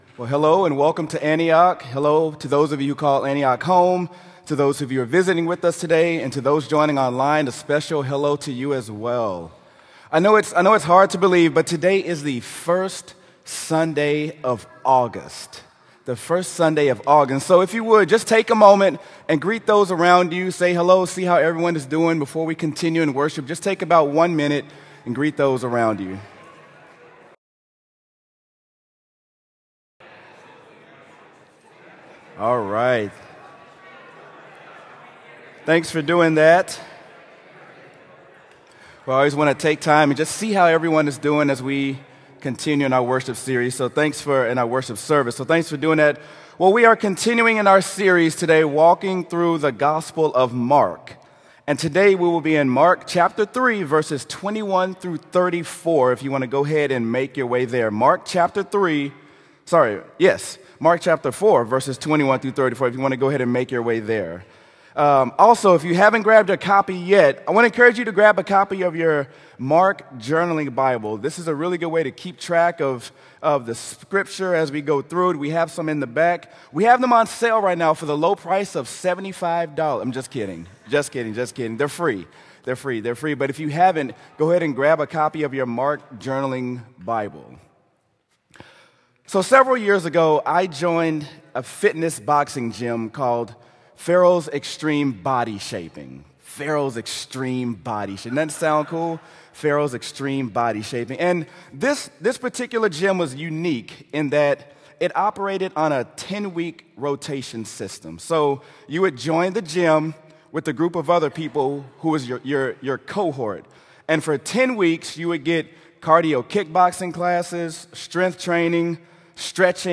sermon-mark-dont-be-a-lazy-christian.m4a